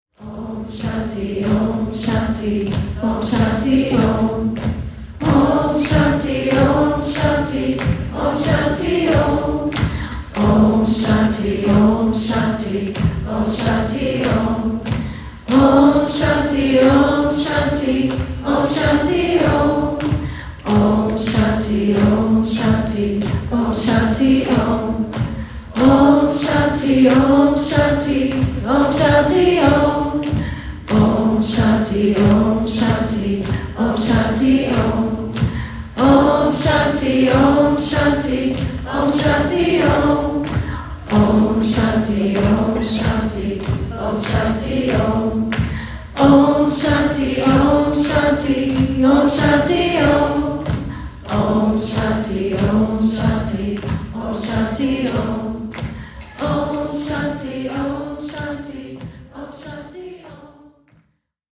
If so, join us for these 14 hours of nourishing, uplifting, and transformative highlights from the miraculous 5-day retreat.
sacred healing chant of peace